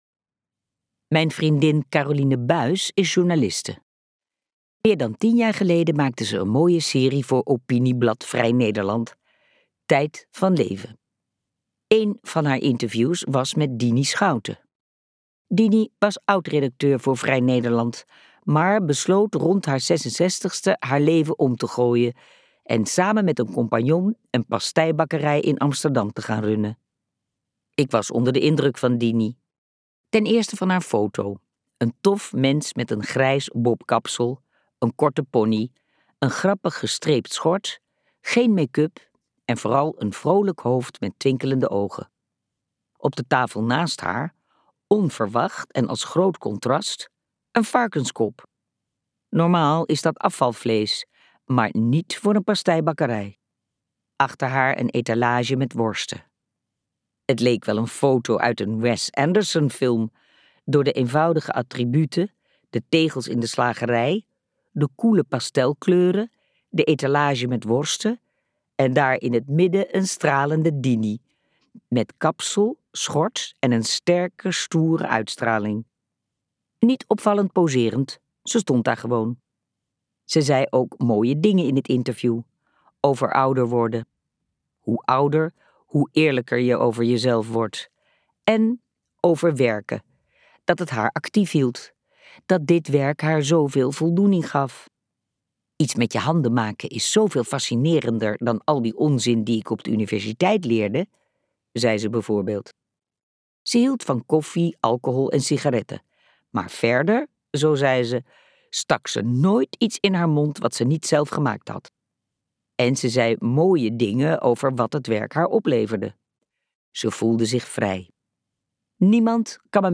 Ambo|Anthos uitgevers - Langer werken langer gelukkig luisterboek